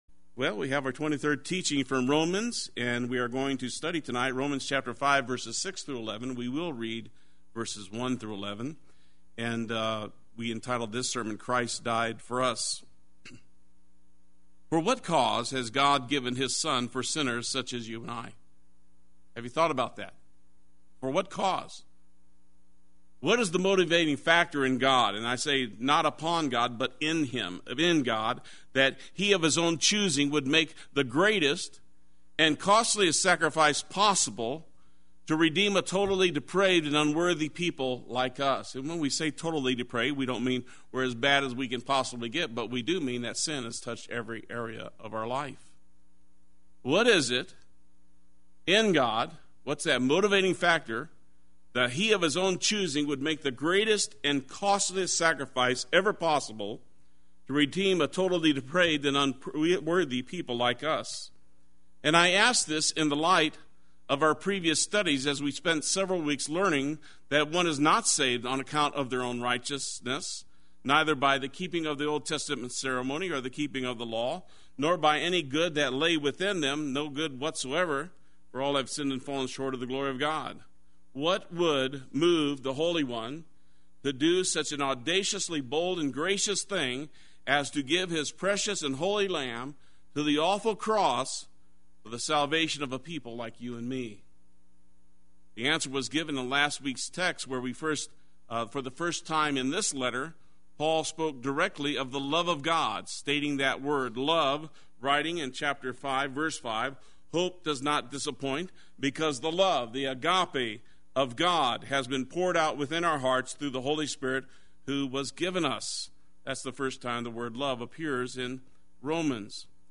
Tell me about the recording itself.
Christ Died For Us Wednesday Worship